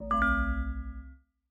steeltonguedrum_ac1.ogg